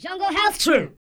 08 RSS-VOX.wav